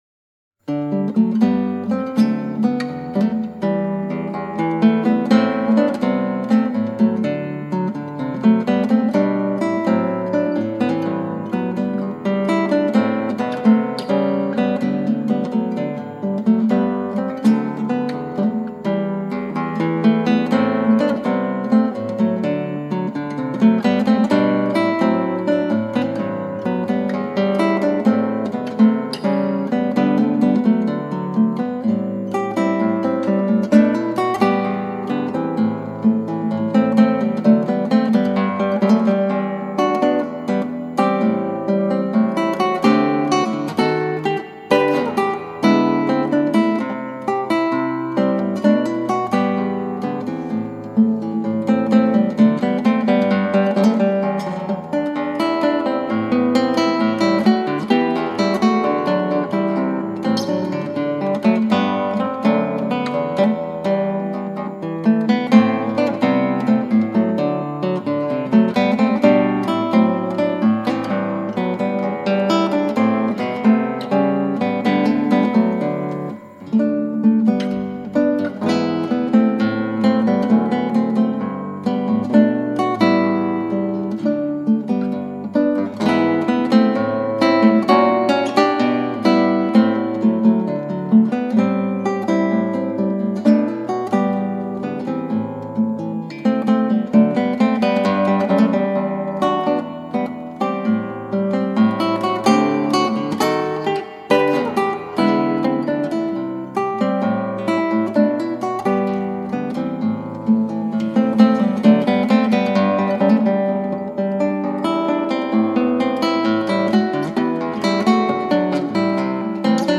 クラシックギター　ストリーミング　コンサート
穏やかなやさしい曲です。